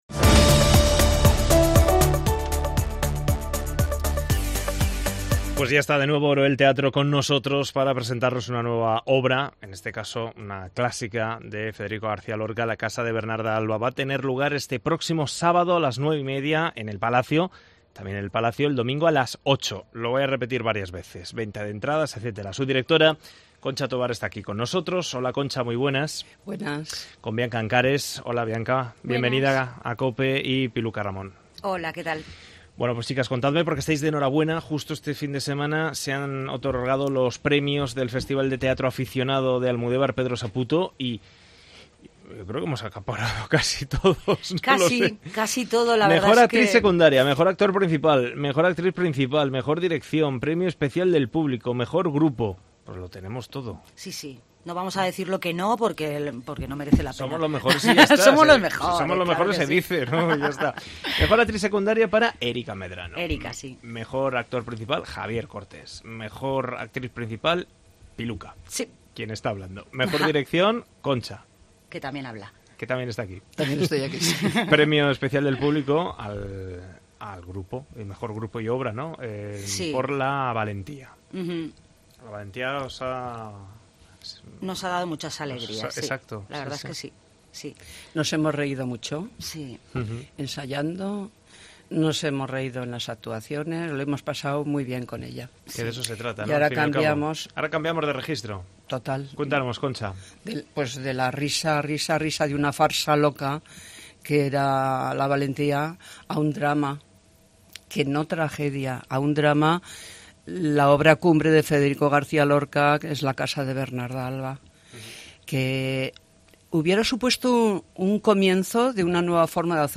actrices de Oroel Teatro, han pasado por los micrófonos de COPE para hablar de la representación de la obra de Federico García Lorca "La Casa de Bernarda Alba".